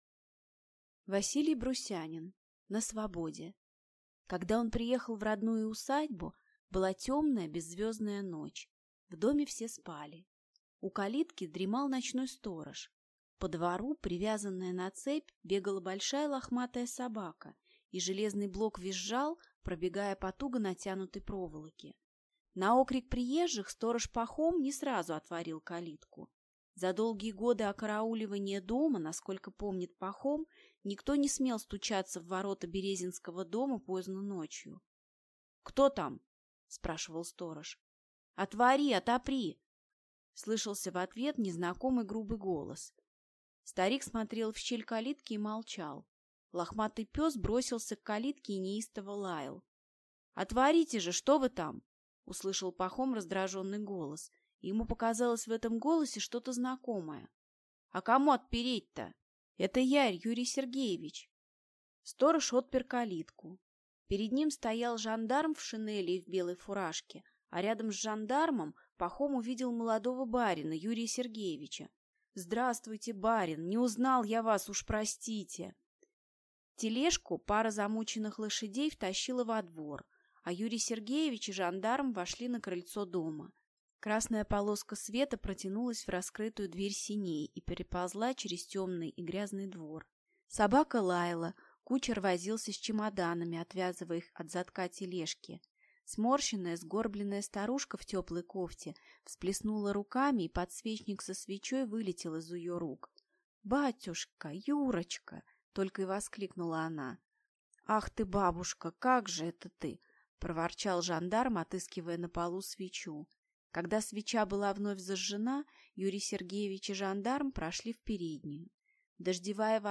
Аудиокнига На свободе | Библиотека аудиокниг